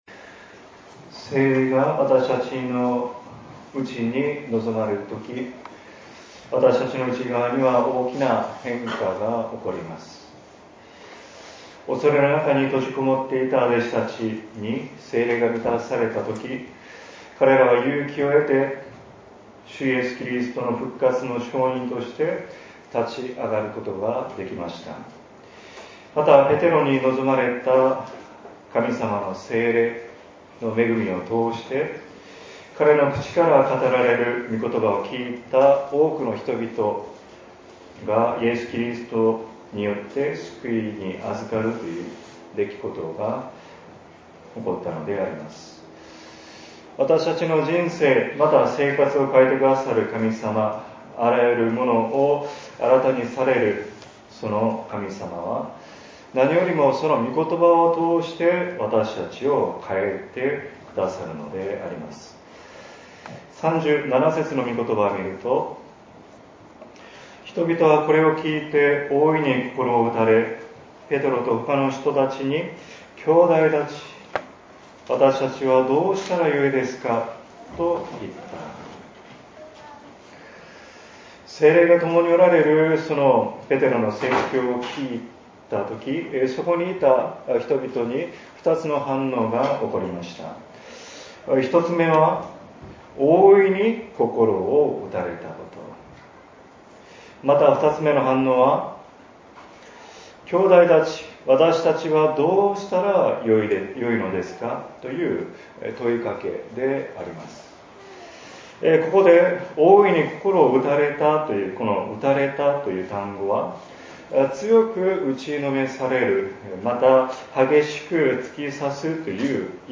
礼拝次第